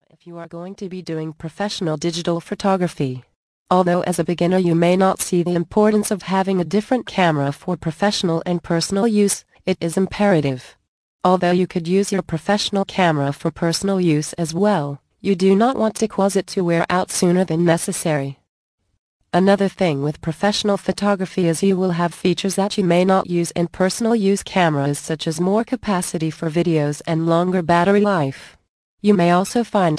Digital Cameras for Beginners audio book + FREE Gift